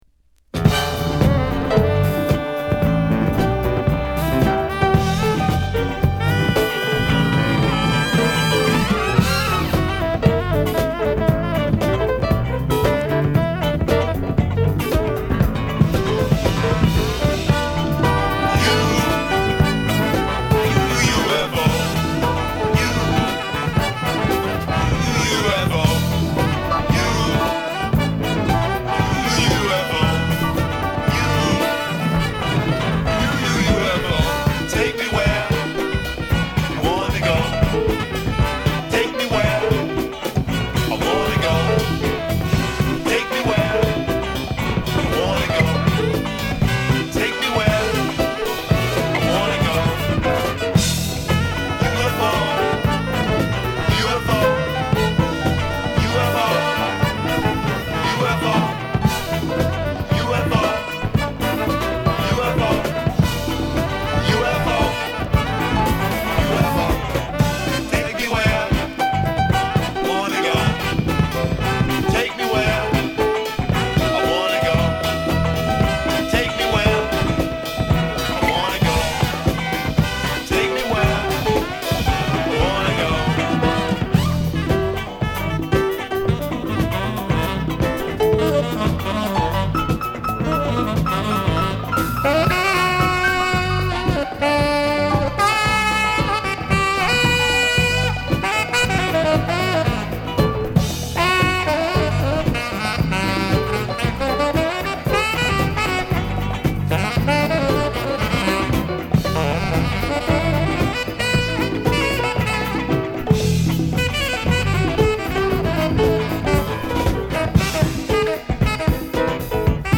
雰囲気満点のゆったりしたリズムに添える女性ヴォーカルが乙！